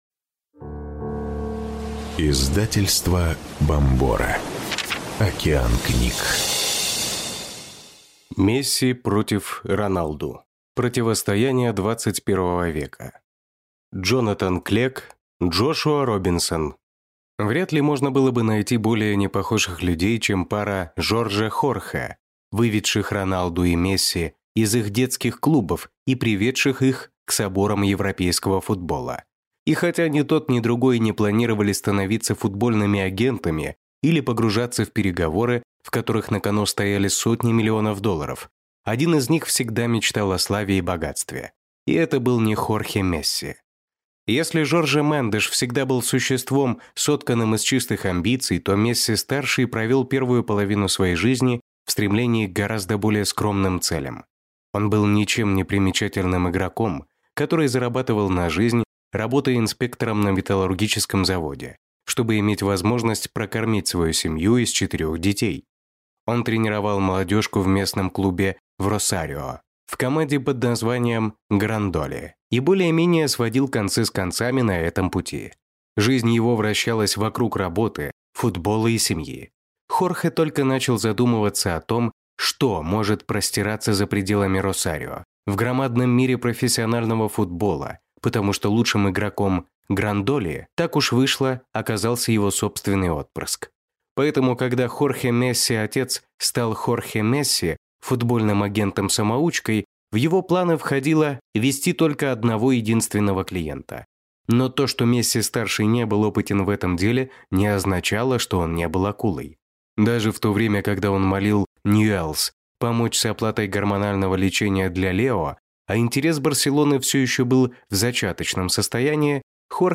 Aудиокнига Месси против Роналду.